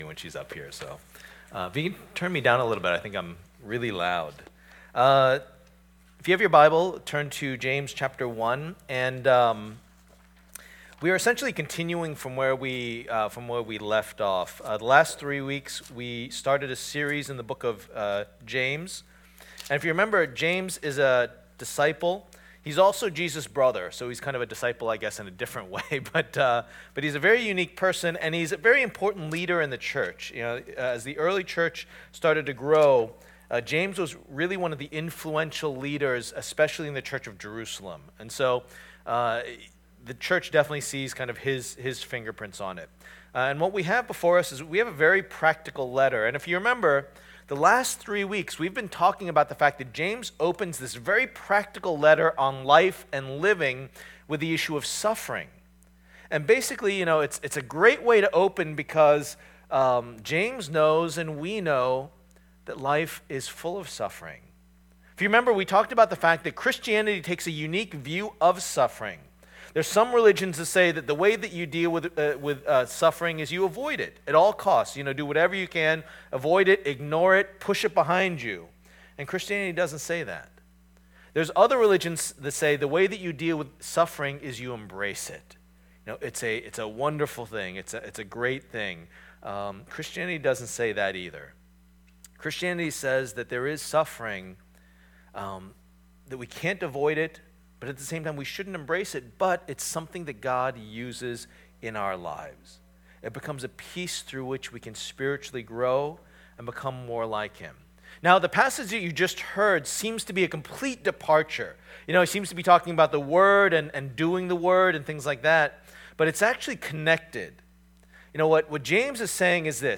Passage: James 1:19-27 Service Type: Lord's Day